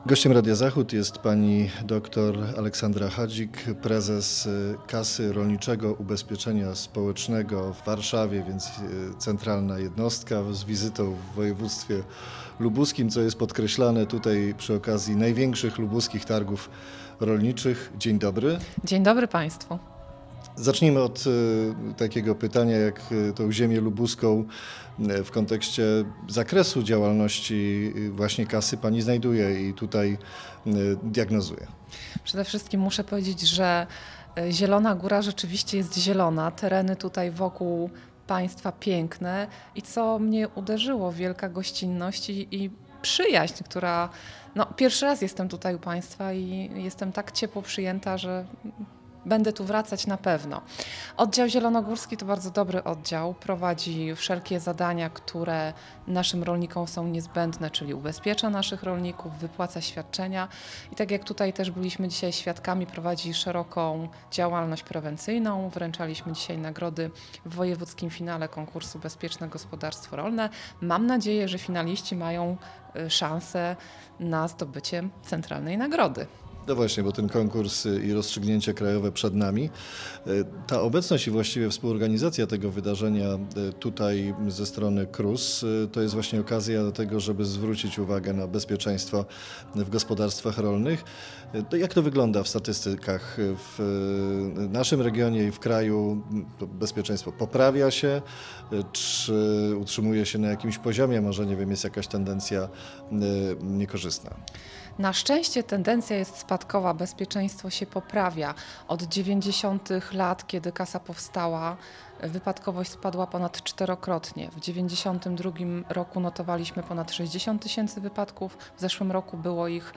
Z prezes Kasy Rolniczego Ubezpieczenia Społecznego, podczas Agro - Targ 2019 w Kalsku,
Aleksandra Hadzik, prezes Kasy Rolniczego Ubezpieczenia Społecznego